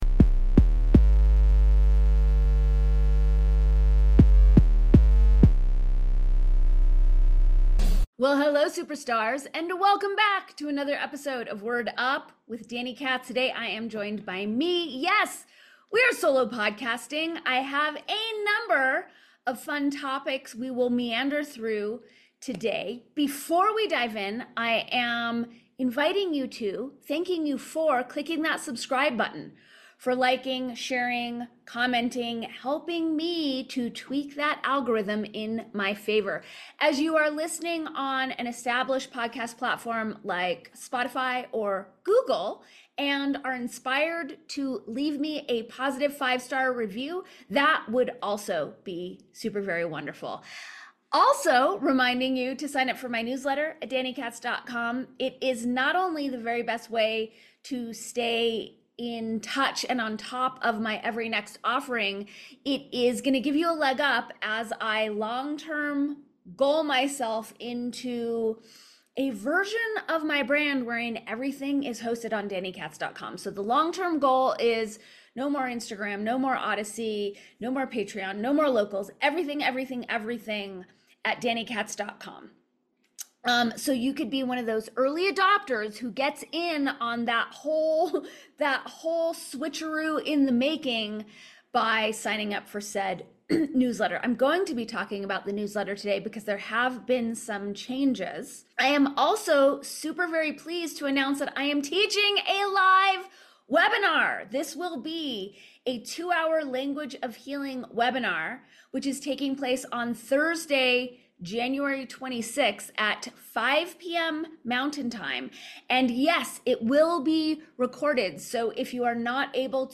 A solo pod romp through the myriad ways we are programming ourselves and others, and being programmed with weaponized (or simply unconscious) languaging habits.